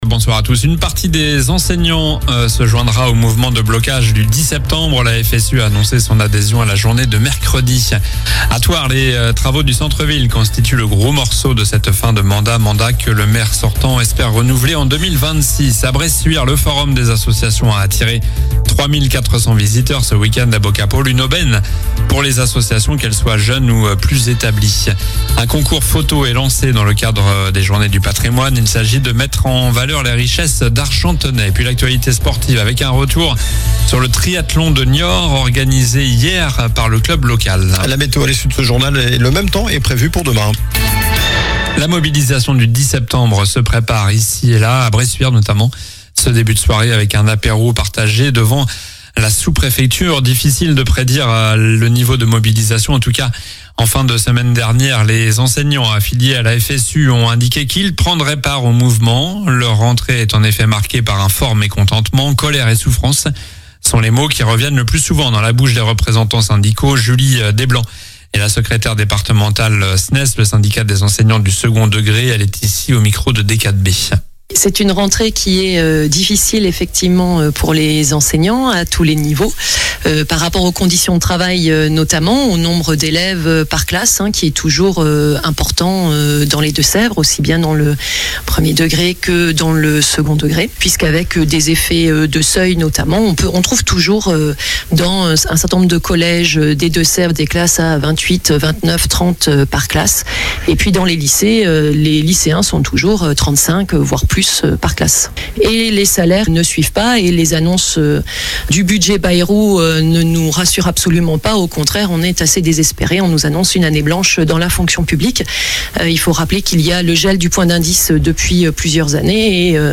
Journal du lundi 08 septembre (soir)